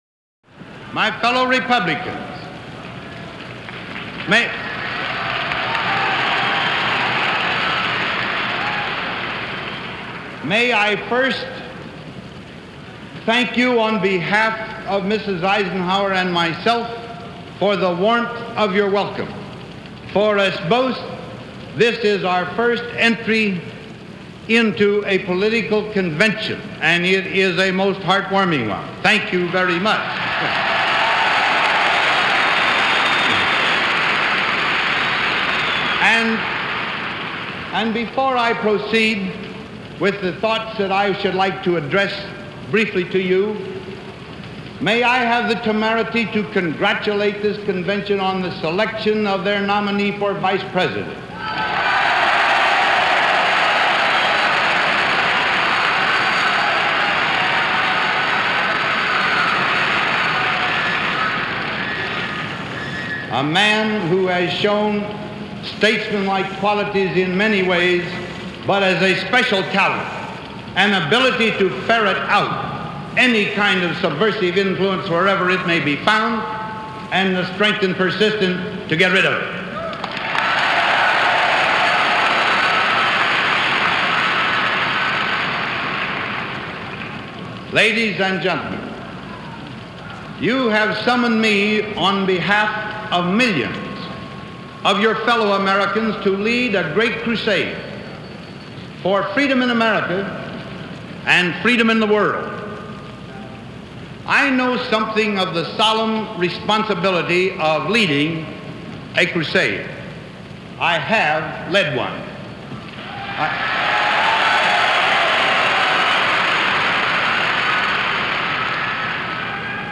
Gen. Dwight Eisenhower accepts the GOP nomination for President of the U.S. - July 11, 1952 - Past Daily Convention Snapshot - "I Accept The Nomination"
Eisenhower-1952-Convention-Acceptance.mp3